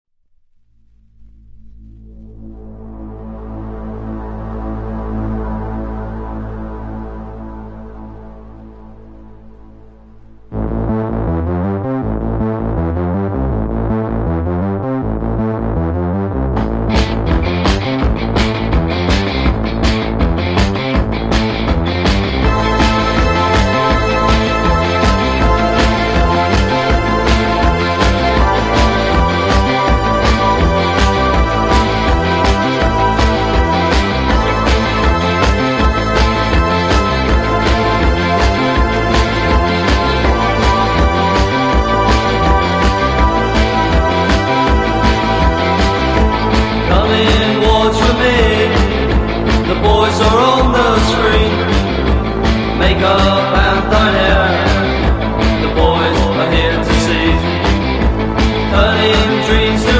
Synth-pop. New Wave. Post Punk.